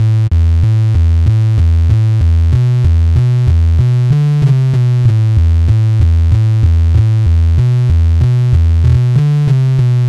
厚重的恍惚低音
描述：低音，中间厚而有质感
标签： 合成器 低音厚实 恍惚
声道立体声